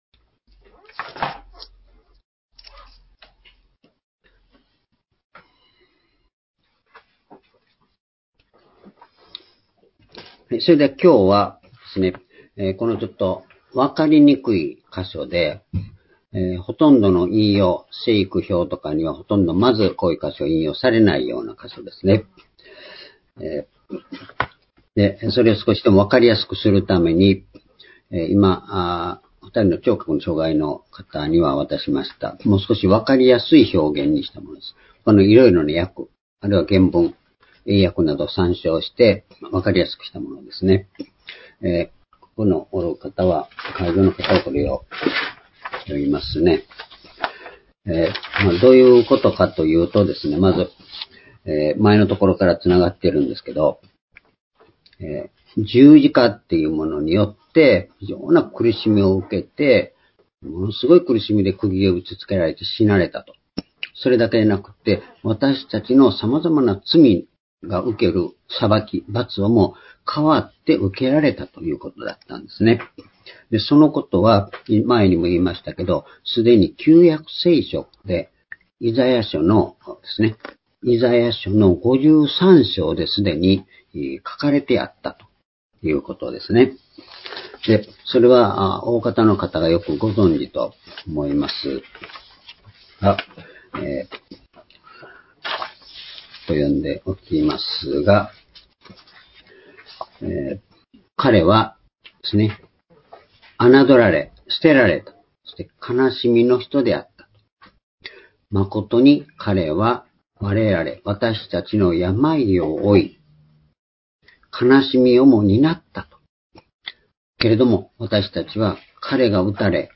「旧約聖書はキリストを指し示す」コロサイ書２章14節～19節-2021年5月23日（主日礼拝）
主日礼拝日時 2021年5月23日（主日礼拝） 聖書講話箇所 「旧約聖書はキリストを指し示す」 コロサイ書２章14節～19節 ※視聴できない場合は をクリックしてください。